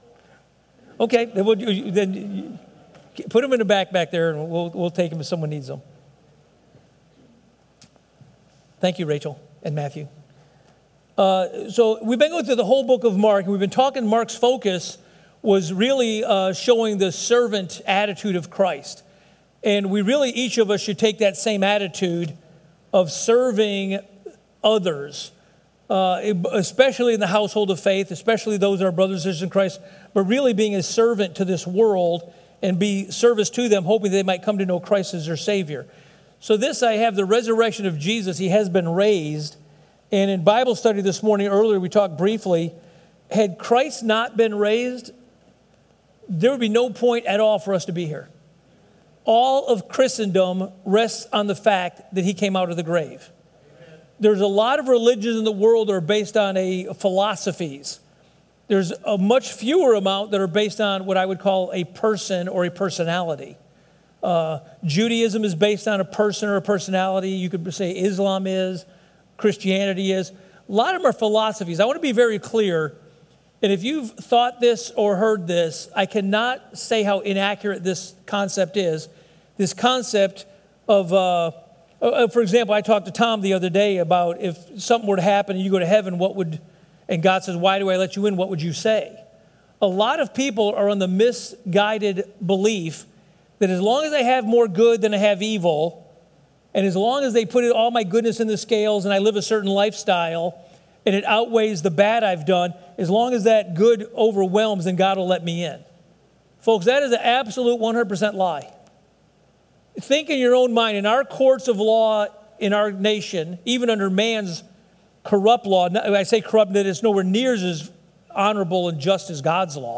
Series Easter Service